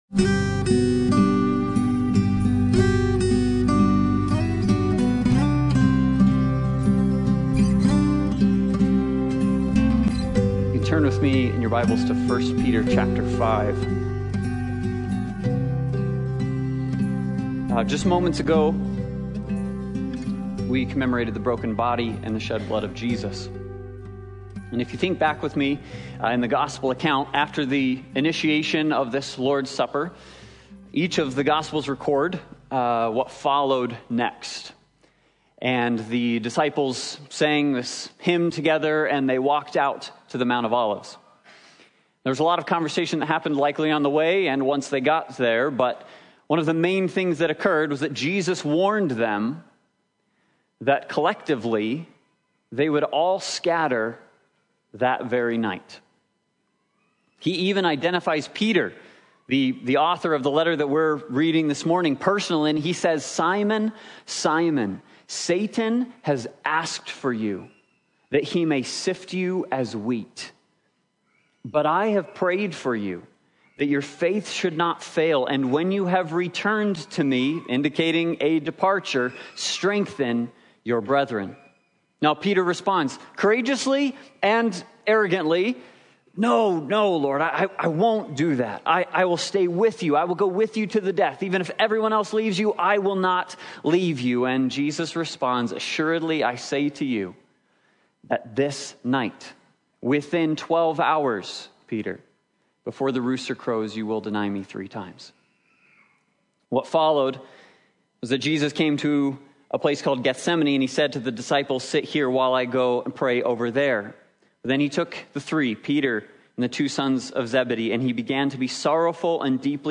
1 Peter 5:8-9 Service Type: Sunday Morning Worship « God’s Mighty Hand